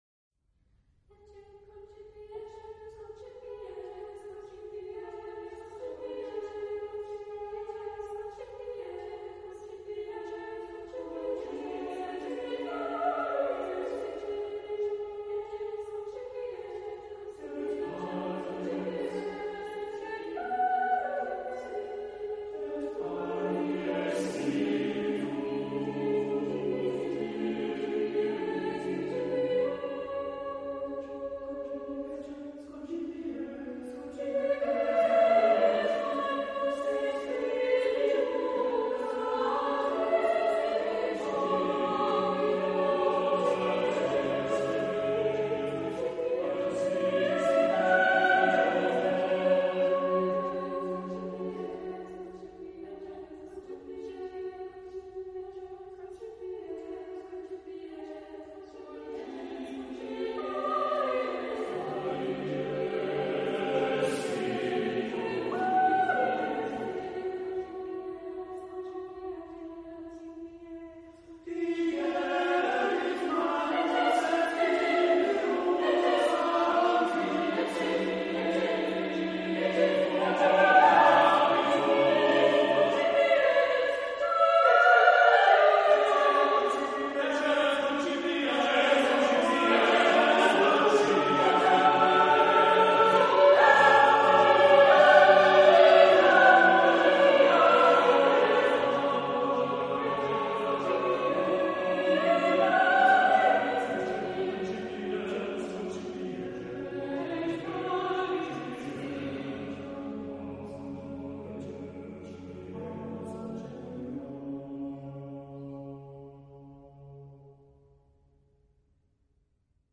Genre-Style-Forme : Sacré ; Chœur ; Motet
Caractère de la pièce : rapide ; énergique
Type de choeur : SSAATTBB  (8 voix mixtes )
Tonalité : libre